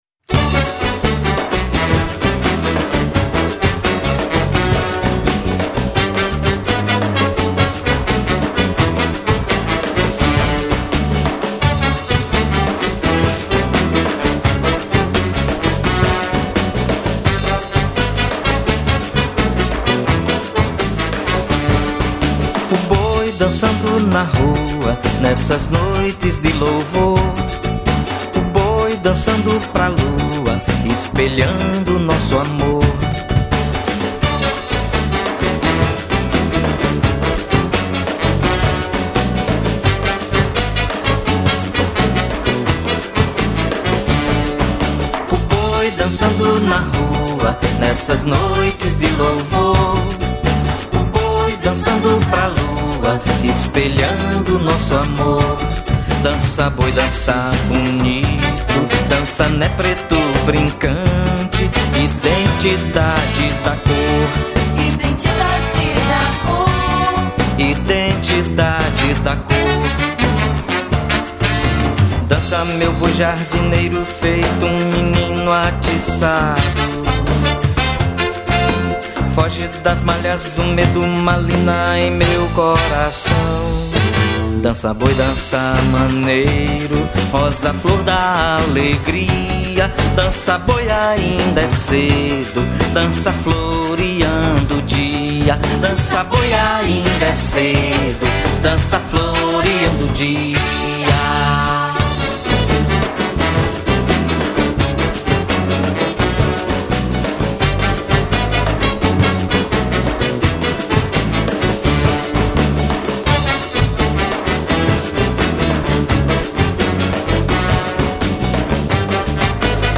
654   03:43:00   Faixa: 1    Boi Bumbá
Trompete
Saxofone Baixo, Saxofone Tenor
Trombone de Vara
Cavaquinho